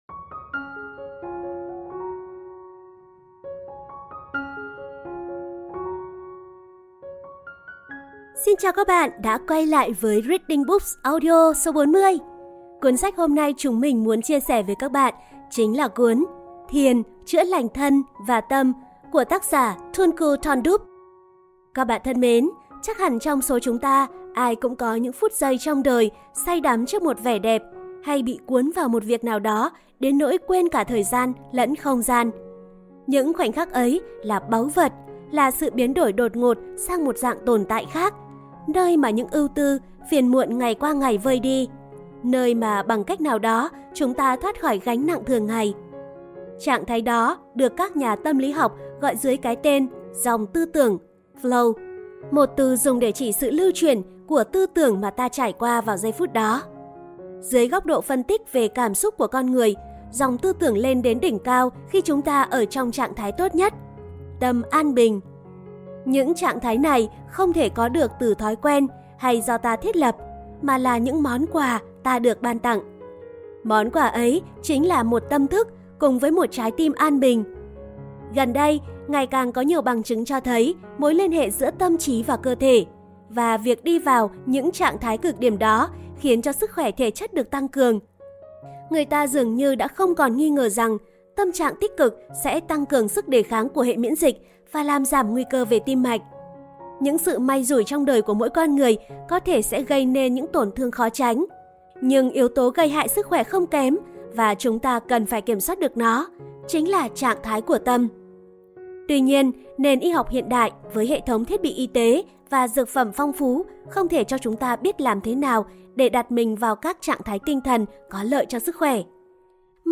[Audiobook] Thiền chữa lành thân và tâm.mp3 – THƯỜNG HỶ LÊ NIÊN
Audiobook-Thien-chua-lanh-than-va-tam.mp3